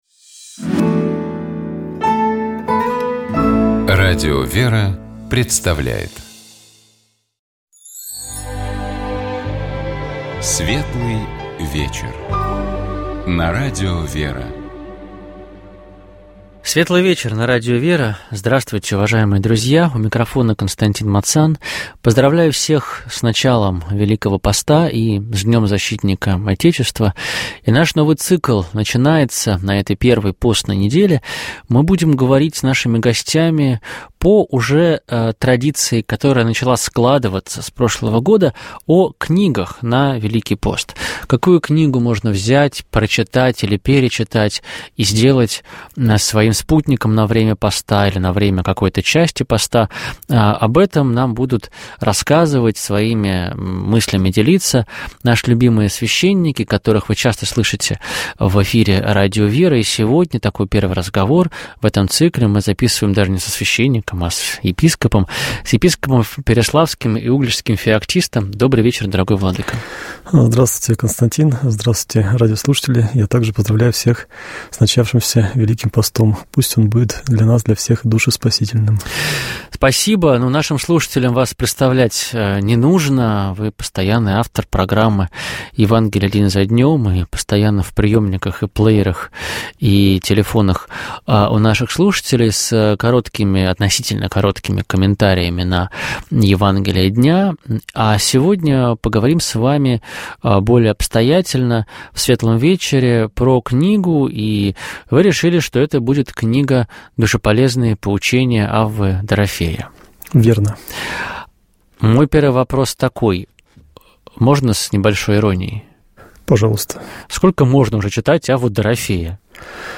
Программа «Светлый вечер» — это душевная беседа ведущих и гостей в студии Радио ВЕРА.